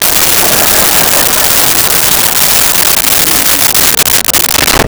Crowd Laughing 01
Crowd Laughing 01.wav